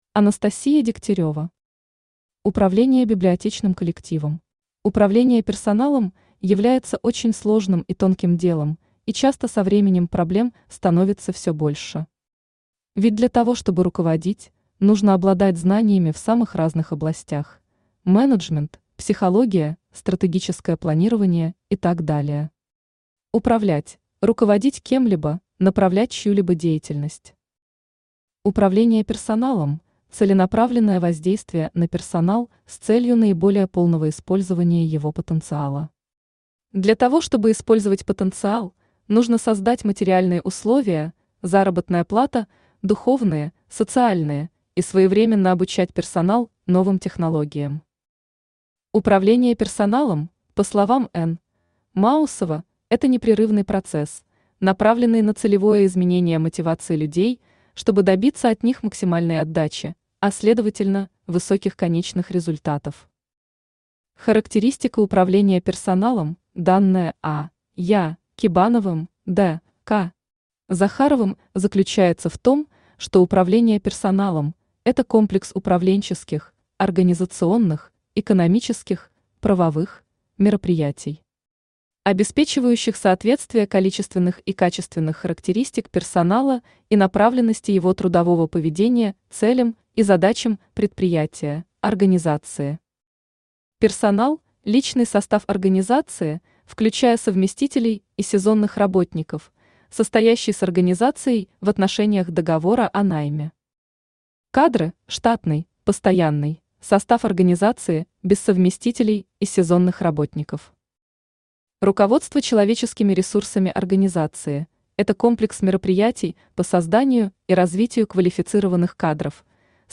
Аудиокнига Управление библиотечным коллективом | Библиотека аудиокниг
Aудиокнига Управление библиотечным коллективом Автор Анастасия Александровна Дегтярева Читает аудиокнигу Авточтец ЛитРес.